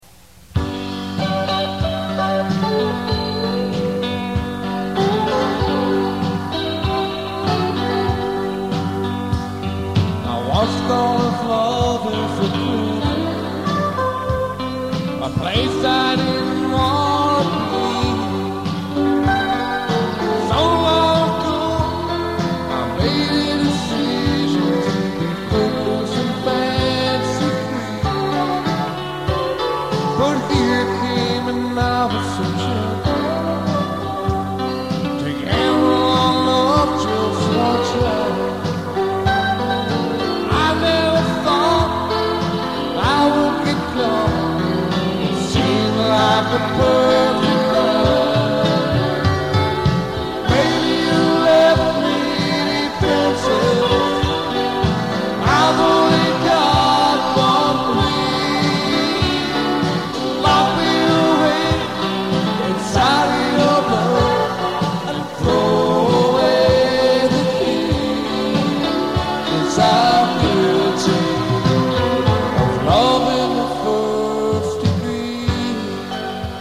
Country Stuff